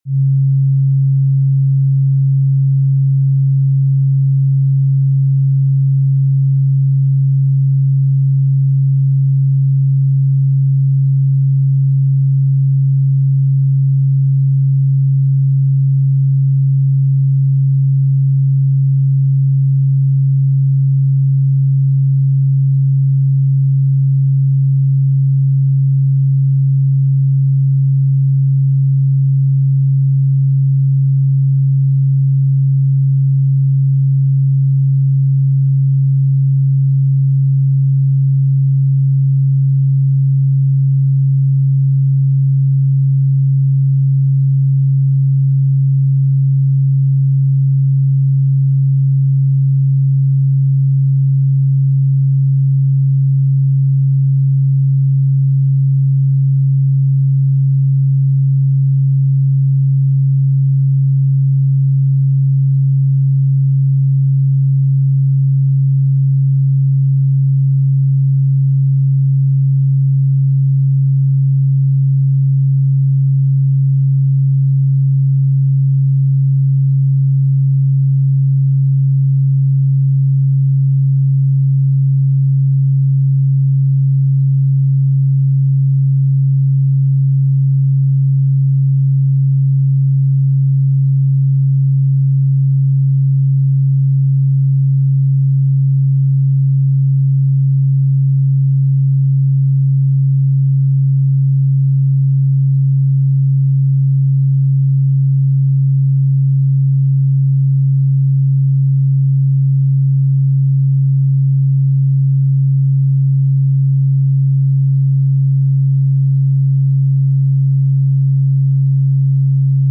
Align with the heartbeat of the Earth at 136.1 Hz – the frequency of deep peace, meditation, and inner balance.